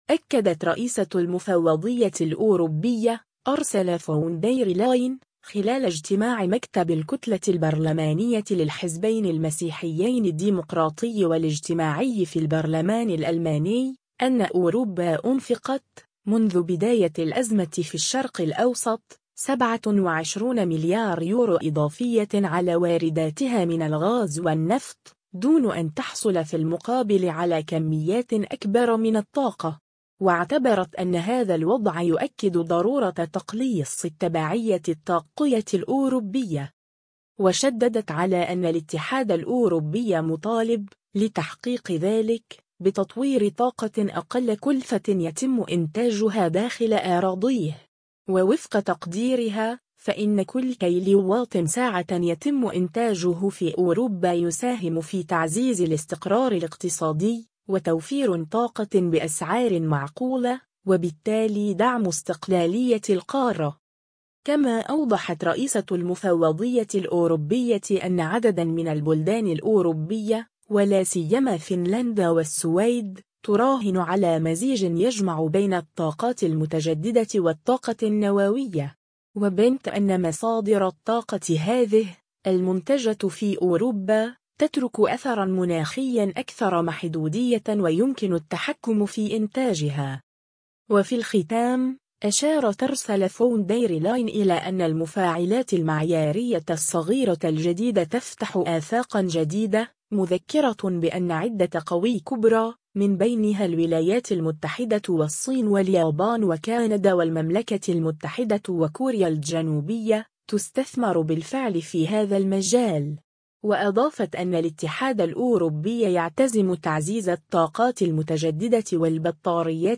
أكدت رئيسة المفوضية الأوروبية، أورسولا فون دير لاين، خلال اجتماع مكتب الكتلة البرلمانية للحزبين المسيحيين الديمقراطي والاجتماعي في البرلمان الألماني، أنّ أوروبا أنفقت، منذ بداية الأزمة في الشرق الأوسط، 27 مليار يورو إضافية على وارداتها من الغاز والنفط، دون أن تحصل في المقابل على كميات أكبر من الطاقة.